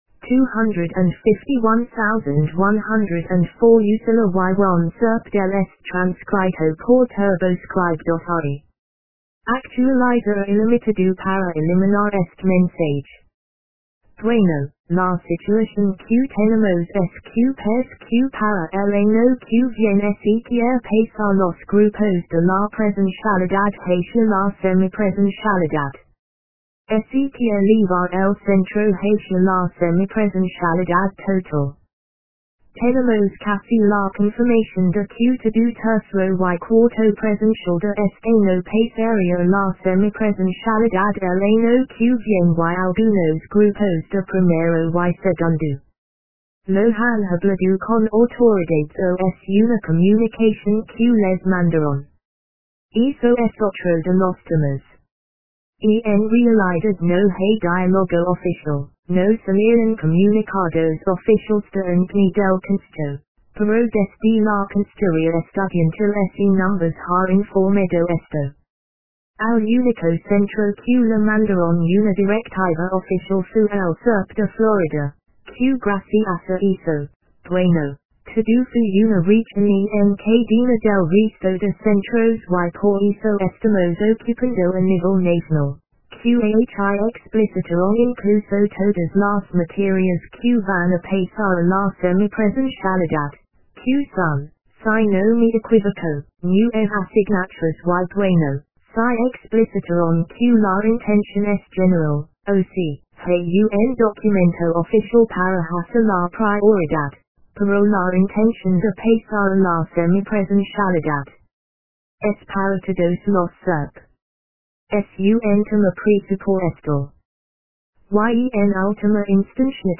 estudiantes de profesorado